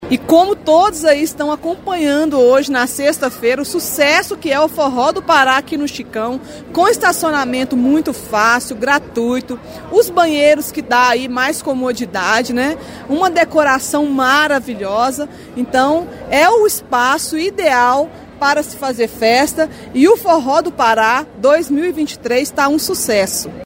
Os elogios deixaram satisfeita a secretária de Cultura, Andreia Paulino: